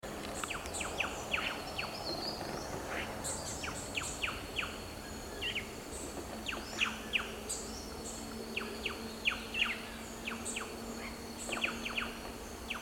Plush-crested Jay (Cyanocorax chrysops)
Life Stage: Adult
Location or protected area: Parque Provincial Urugua-í
Condition: Wild
Certainty: Recorded vocal